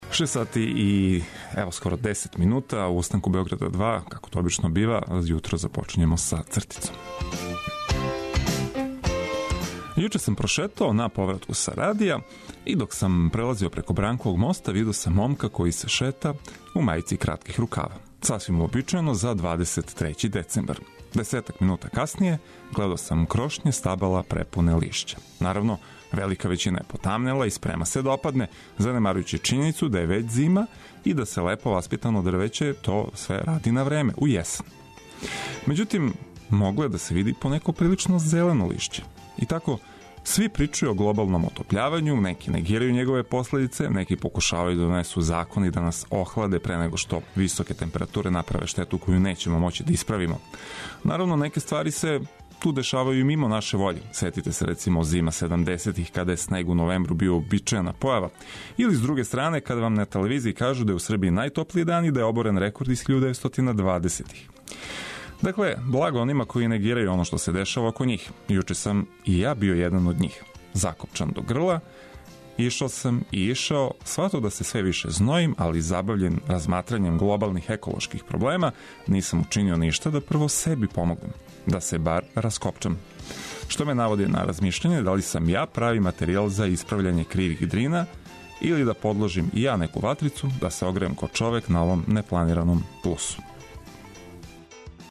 Наравно, уз вашу помоћ - слушалаца репортера, али и одличну музику са свих страна света!